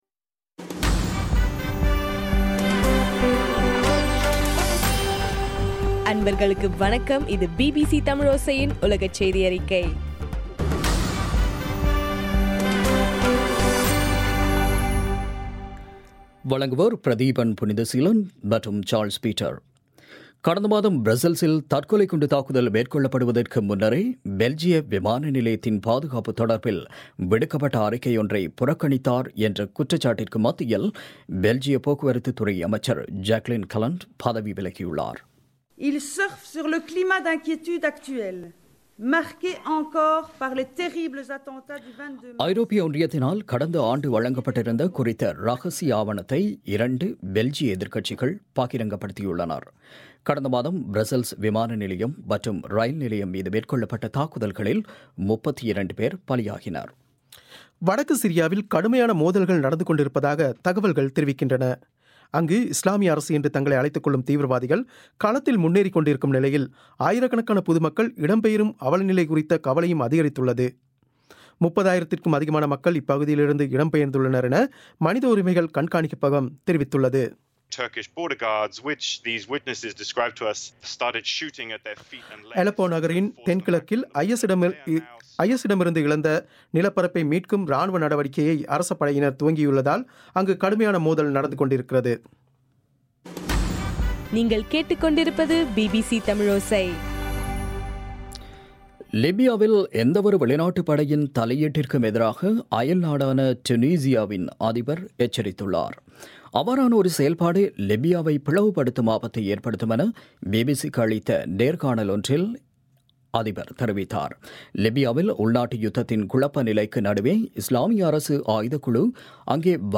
ஏப்ரல் 15 பிபிசியின் உலகச் செய்திகள்